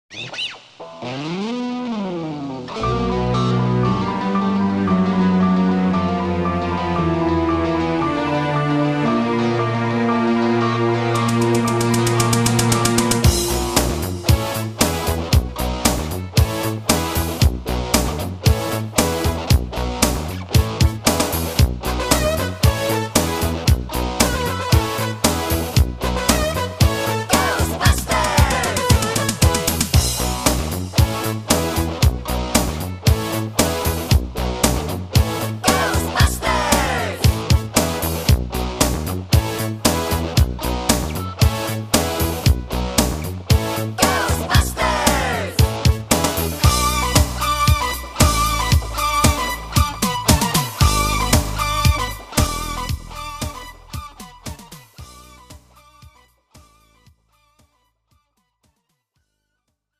축가 및 결혼식에 최적화된 고품질 MR을 제공합니다!